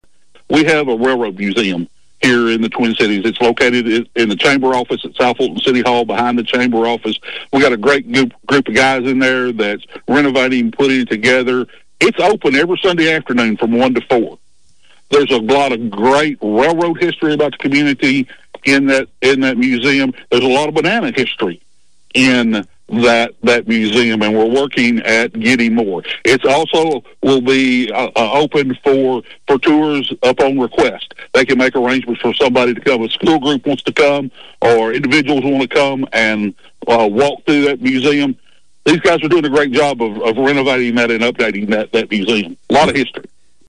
spoke with Thunderbolt News about the museum.